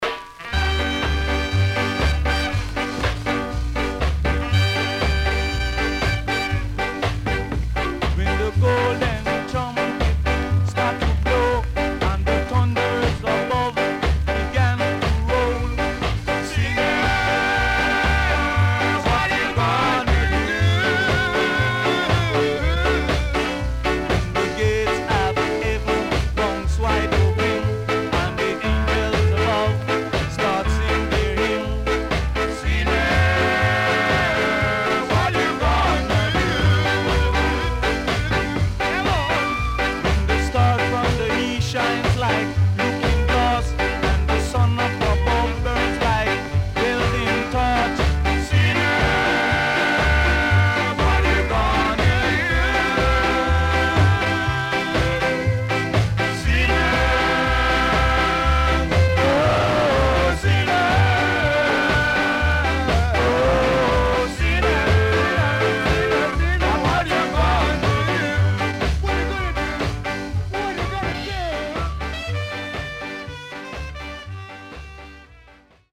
Killer Ska Inst & Nice Duet Ska.W-Side Great
SIDE A:序盤針とびではありませんが引っかかりあります。
うすいこまかい傷ありますがノイズあまり目立ちません。